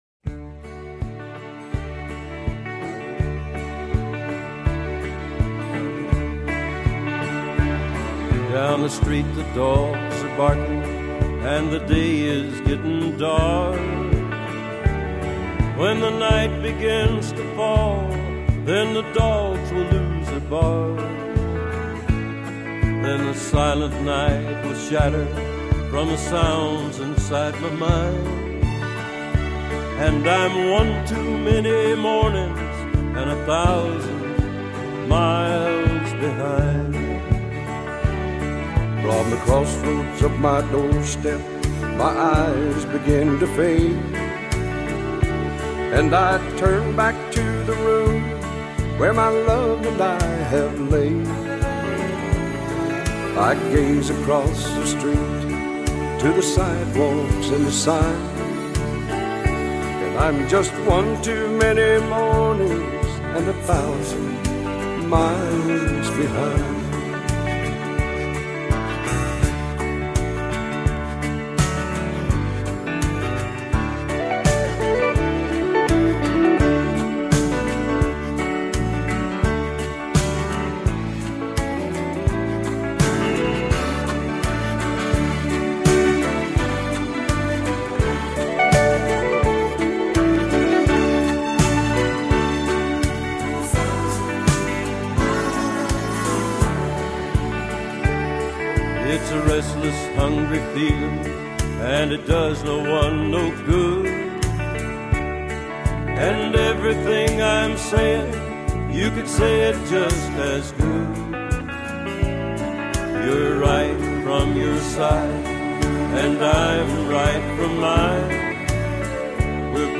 Жанр: Folk, World, & Country